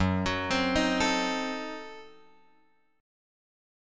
F# Diminished 7th